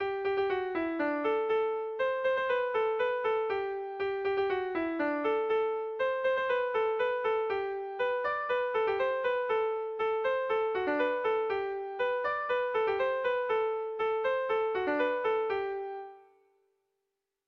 Haurrentzakoa
ABAB